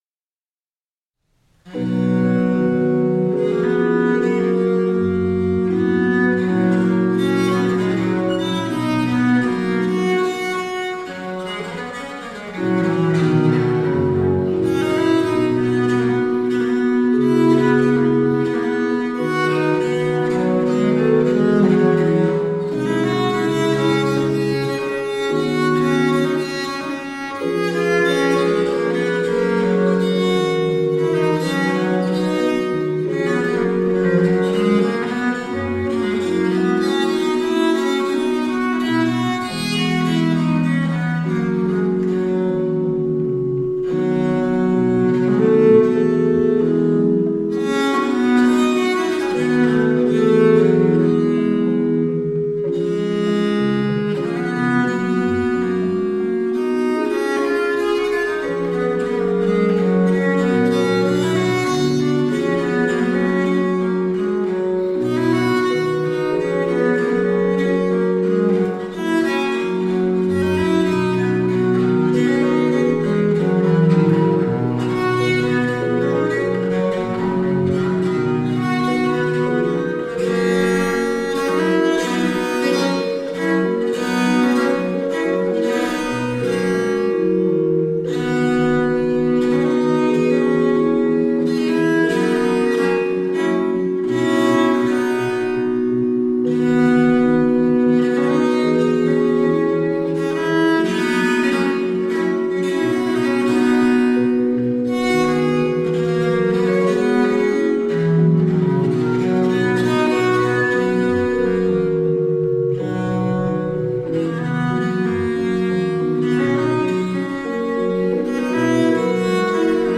German Sonatas for Viola da Gamba from the Baroque period.